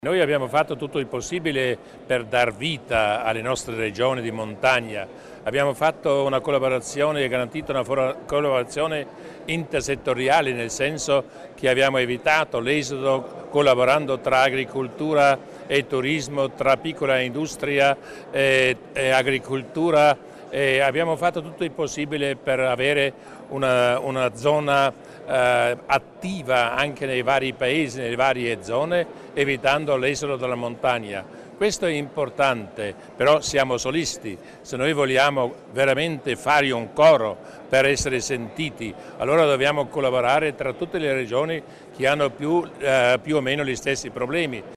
Il Presidente Durnwalder illustra l'importanza di una strategia comune per l'arco alpino